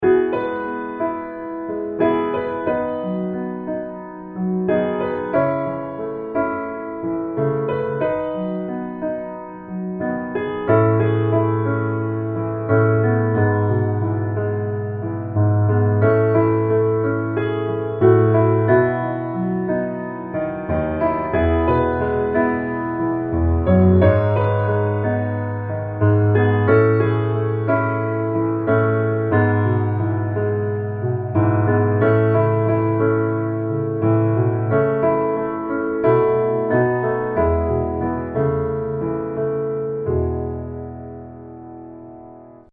E Major